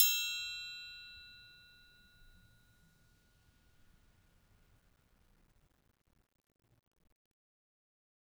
Triangle3-Hit_v1_rr1_Sum.wav